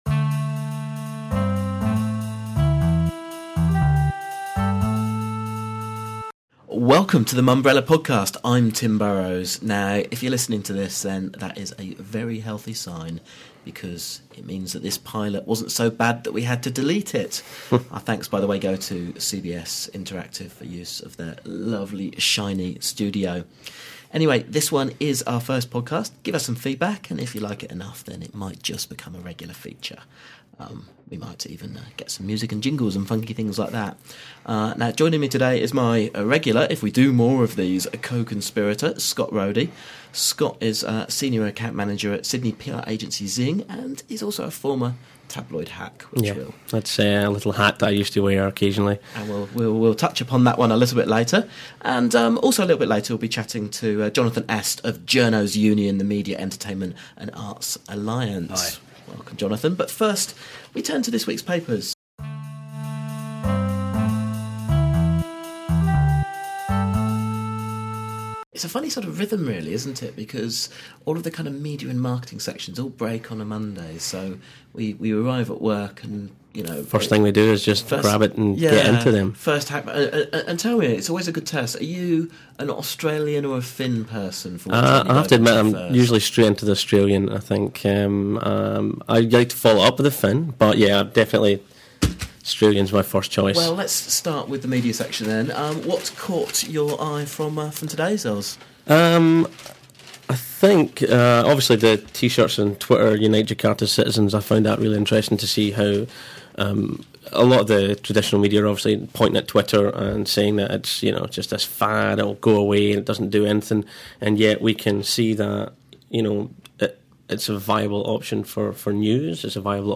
(A few caveats: In the first few seconds, the sound levels are a tad wobbly, so apologies for the distortion.